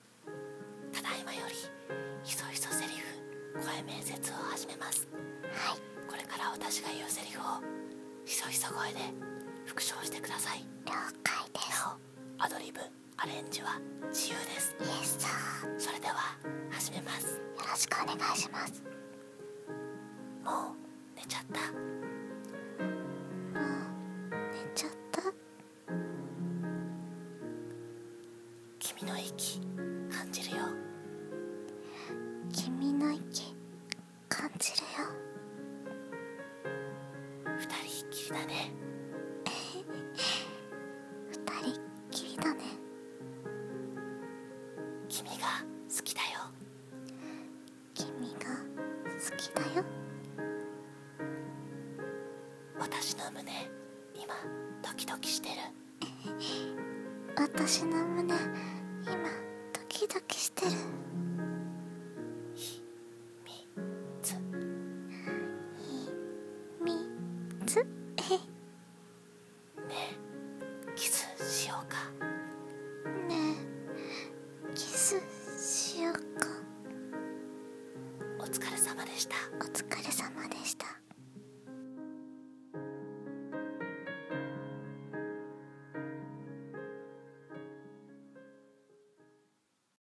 ひそひそセリフ声面接